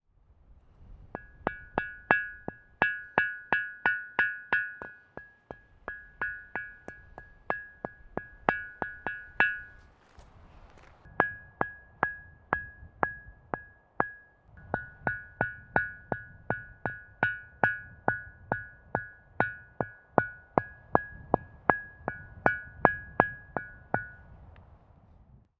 Type: Ringing stone
Location: Skåra, Egersund
At this point, the sound is best, even if it has sound all over. There are two different tones (F # and G #), which can be heard in the last part of the audio recording here.
Listen to the sound from Skåra